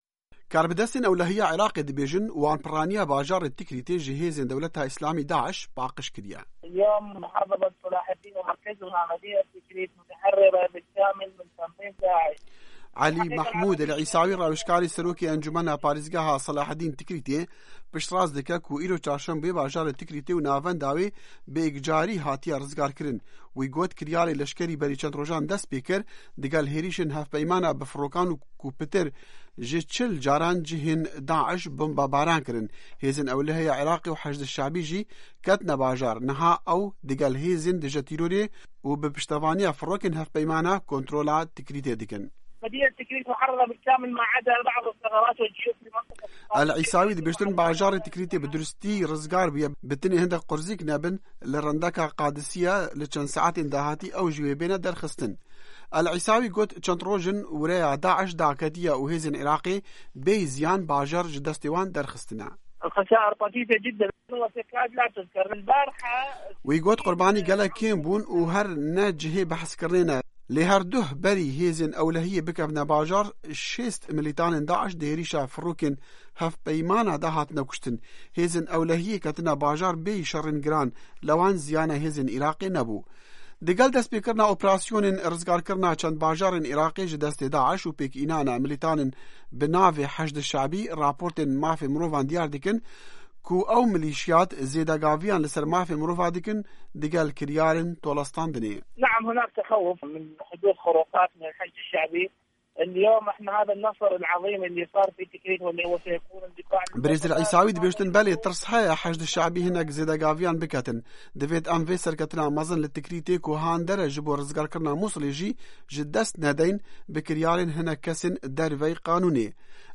Di hevpeyvînekê de ligel Dengê Amerîka şewirmendê Encumena Parêzgehê Alî Mehmude el-Îsawî got, navenda bajêr aram e û karmendên hikûmetê dê dest bi karên xwe bikin.